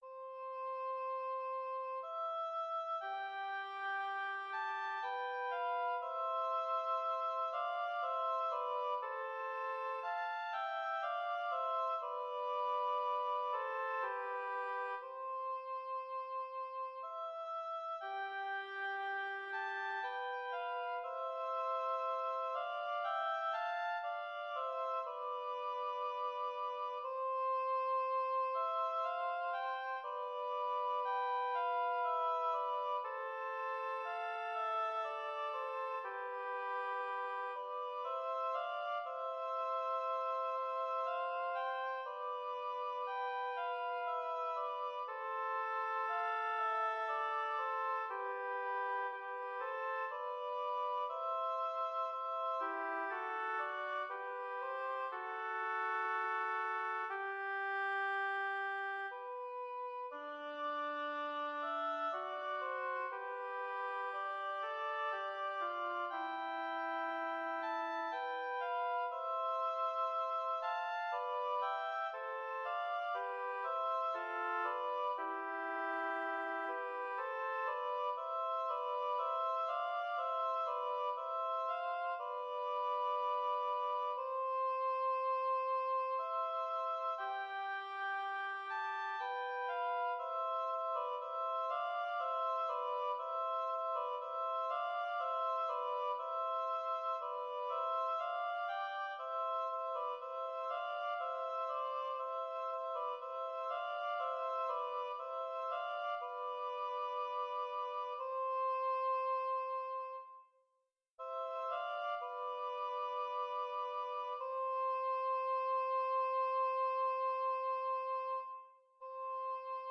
Intermediate oboe duet
oboe music